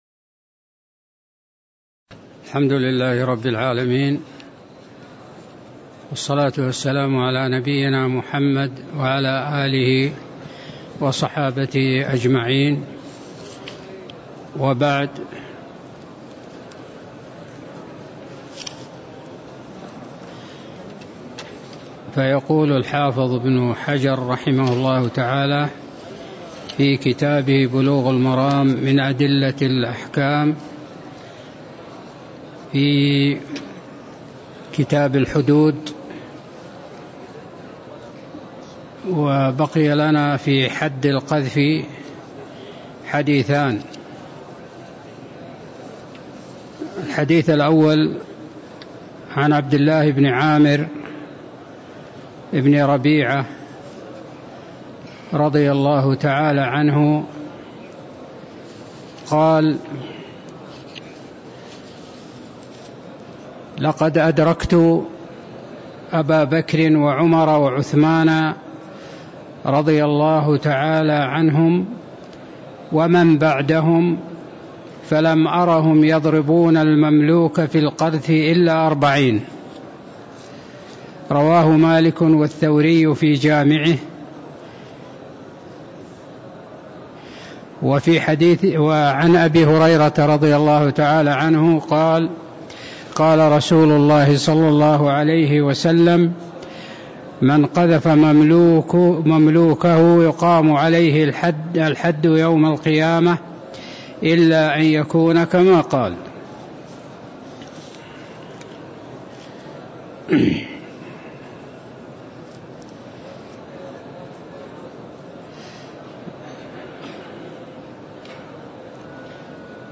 تاريخ النشر ١٣ رجب ١٤٤٣ هـ المكان: المسجد النبوي الشيخ